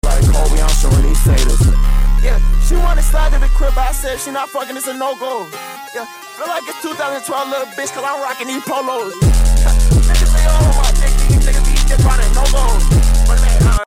PlaqueBoyMax unlocked a new flow while rapping on a beat